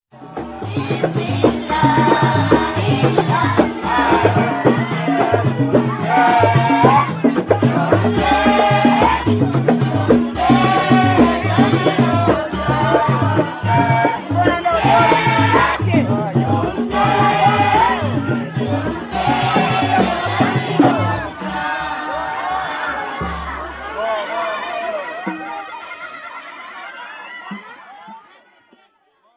Kasida is performed by the pupils of an Islamic school (madarasa), their teachers, and audience members.  It features tuned frame drums and, as the intensity of the music increases, ‘a stylised dance, bringing the boys to their feet, and the girls, behind them, to their knees'.
play Sound Clipand if linguistic and cultural differences mean that maulidi is less immediately accessible to outside listeners, there’s no doubting the enthusiasm it generates among its local audiences; this clip, from the end of the performance, is evidence of that. (sound clip)